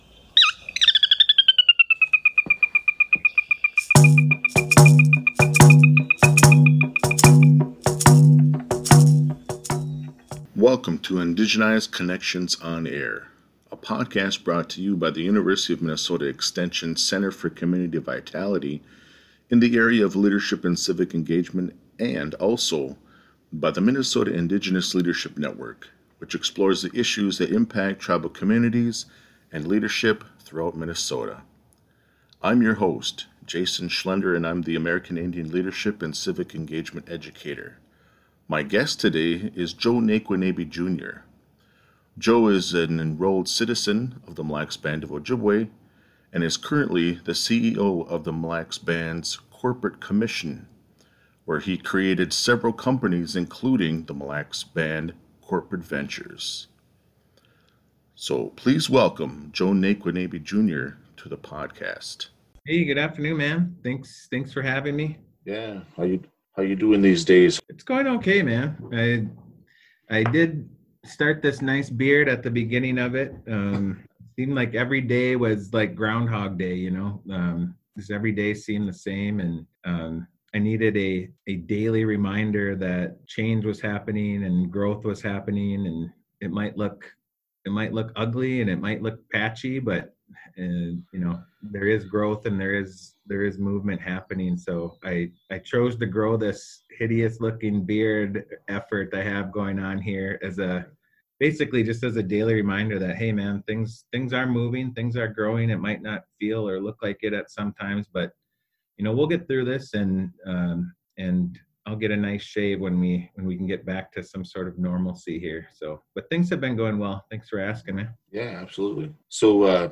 Join us for a conversation